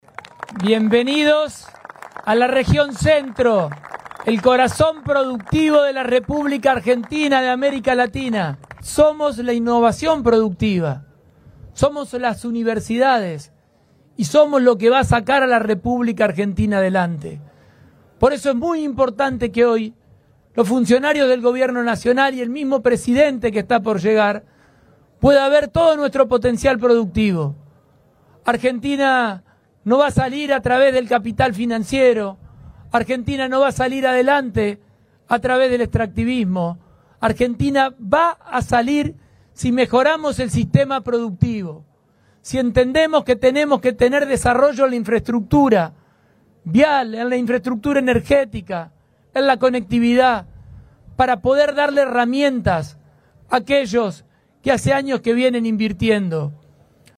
Los mandatarios de Santa Fe, Córdoba y Entre Ríos participaron este jueves del acto inaugural de la muestra Agroactiva, la exposición agropecuaria a cielo abierto más convocante de la Argentina y la región que se desarrolla hasta el 8 de junio en la ciudad de Amstrong.
“Argentina saldrá adelante si mejoramos nuestro sector productivo”, enfatizó el Gobernador de Santa Fe en la apertura de la megamuestra agropecuaria.
AGROACTIVA-GOB-SF-PULLARO.mp3